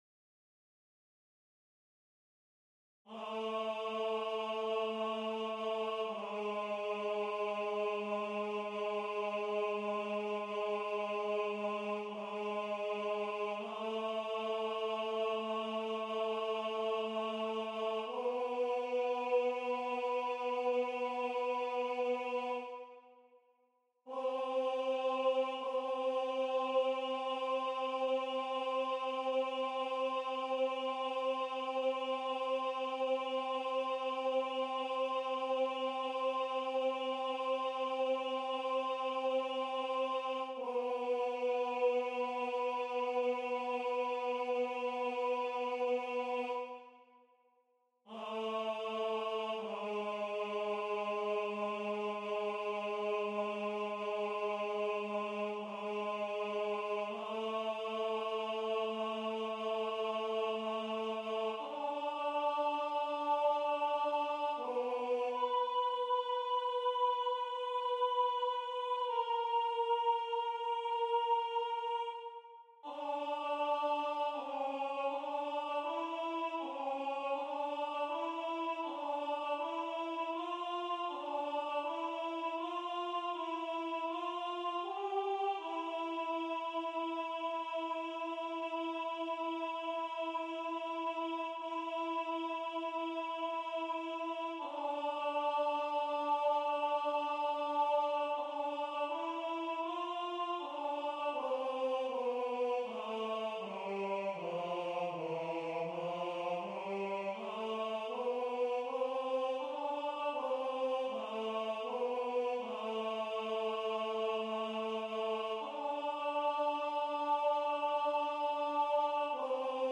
- Œuvre pour chœur à 6 voix mixtes (SAATBB) a capella
MP3 rendu voix synth.
Alto 2